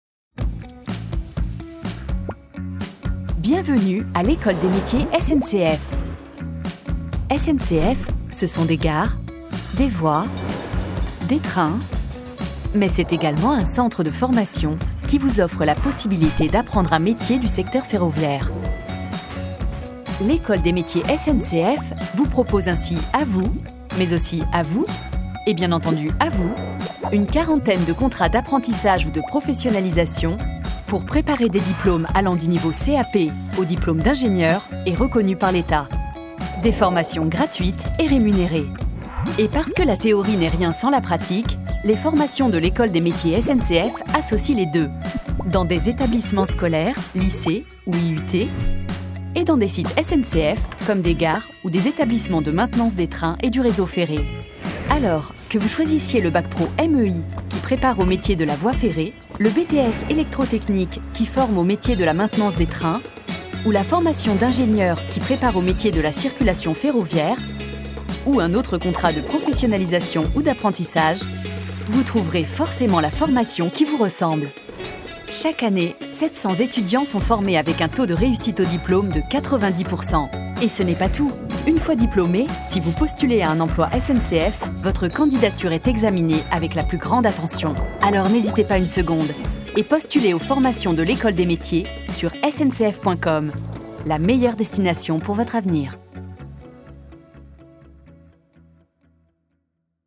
Institutionnel voix complice voix didactique voix dynamique Voix didactique Catégories / Types de Voix Extrait : Votre navigateur ne gère pas l'élément video .